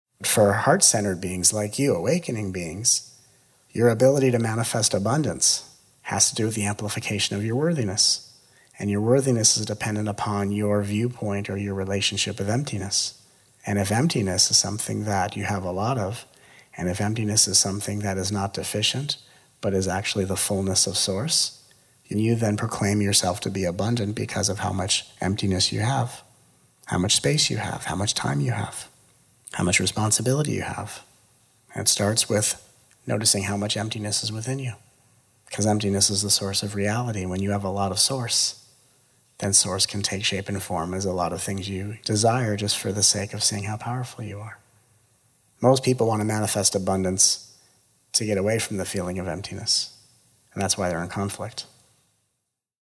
7.The Three Levels of Awakening - Encinitas Immersion (1:42:07)
Encinitas 2017 sample_quotes - The Levels of Awakening - quote4.mp3